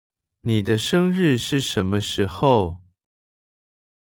Our new text-to-speech provider for the sentence audio is working just fine.
Here is the same sentence with a lowered prosody speed.
This particular phrase sounds very similar speedwise as my google translate version.
I think what we will probably do as a first step is add a setting for sentence playback speed with settings of “normal”, “slow”, and “extra slow” (the one I have above is “extra slow”).